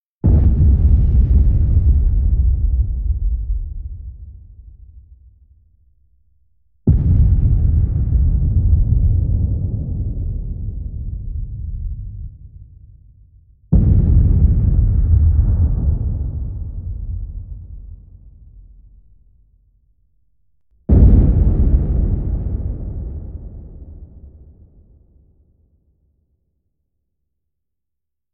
Звук дальних авиаударов